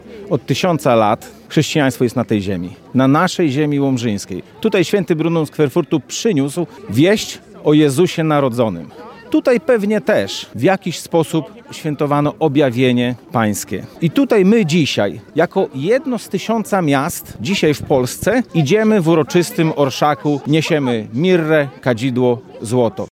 Wójt gminy Łomża Piotr Kłys przypomniał z kolei o wielowiekowej chrześcijańskiej tradycji na ziemi łomżyńskiej.